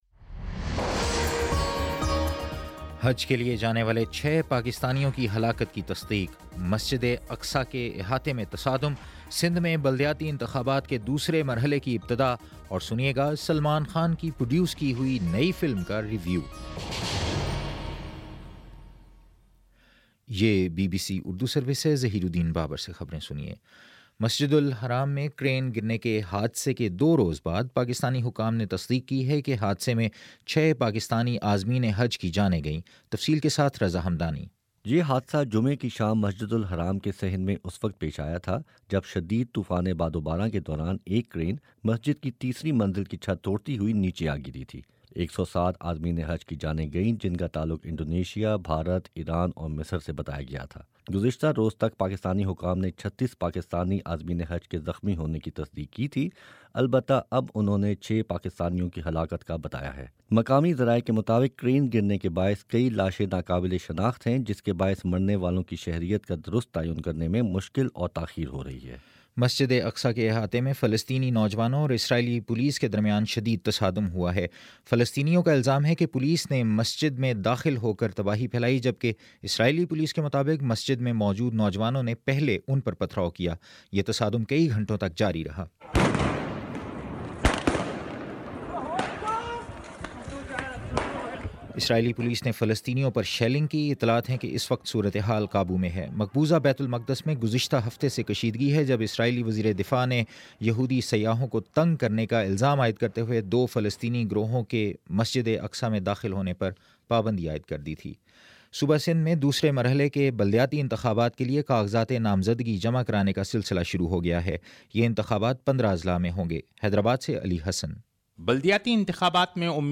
ستمبر13 : شام چھ بجے کا نیوز بُلیٹن